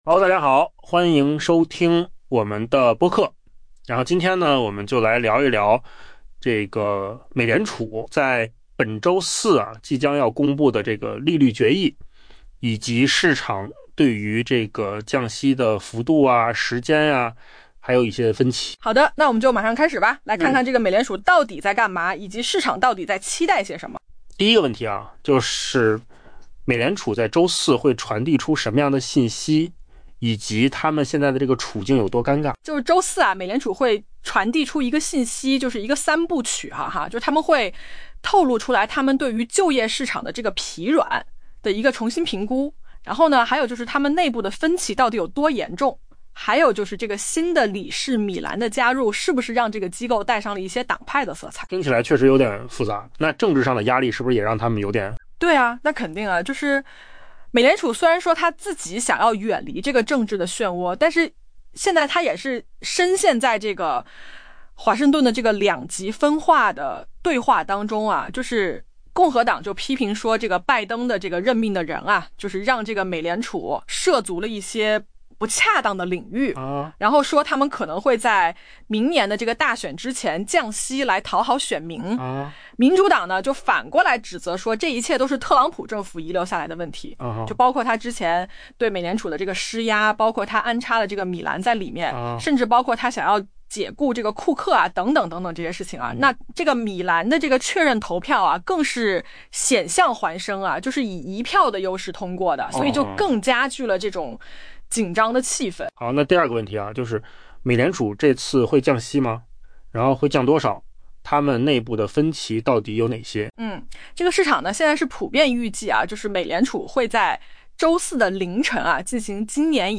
【文章来源：金十数据】AI播客：换个方
AI 播客：换个方式听新闻 下载 mp3 音频由扣子空间生成 周四， 美联储将向投资者传达一个 「三部曲式」 的信息：官员们在多大程度上重新调整其前景以反映疲软的就业市场？